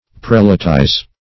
Prelatize \Prel"a*tize\, v. t. [imp. & p. p. Prelatized; p.